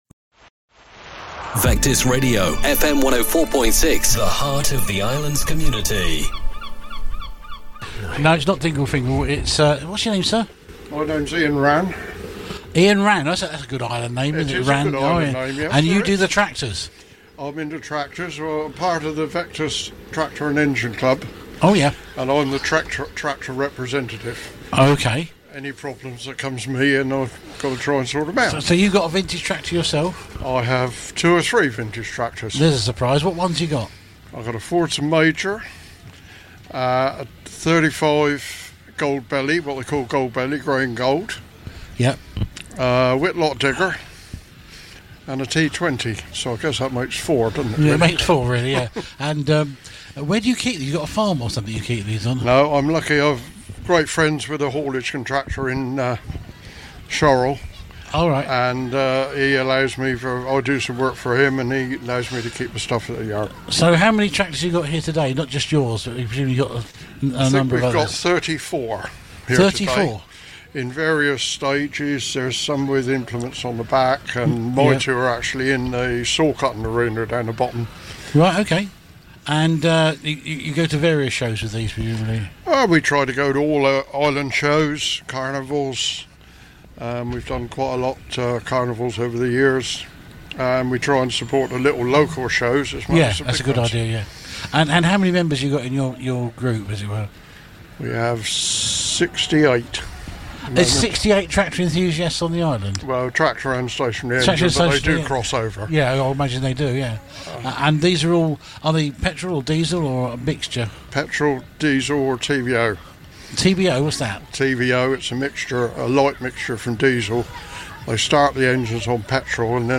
at The Chale Show 2025.